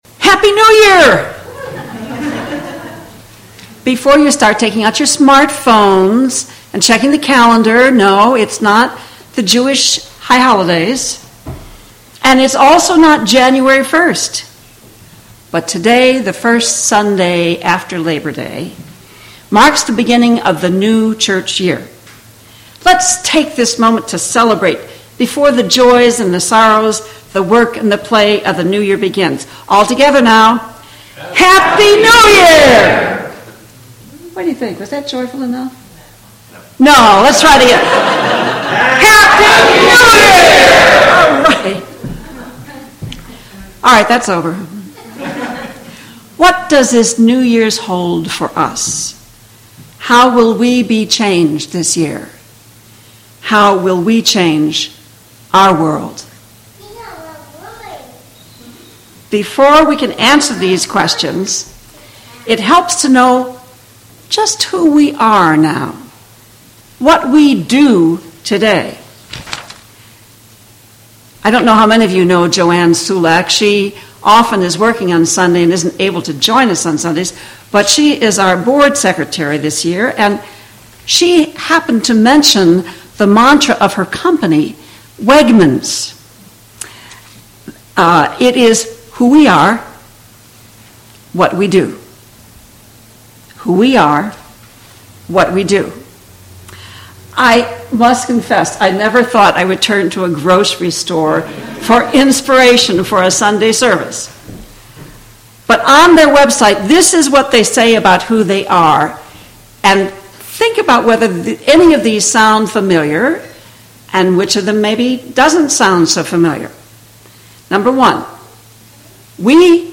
This sermon captures the beginning of a new church year at the Unitarian Universalist Church of Loudoun.
The speaker reflects on the church’s identity, drawing inspiration from the Wegman’s company mantra of “who we are, what we do.” The congregation engages in a discussion to define the core values and actions of their community, emphasizing inclusivity and spiritual growth. The sermon connects these values to their covenant, mission, and principles, advocating for a “works theology” where actions reflect beliefs.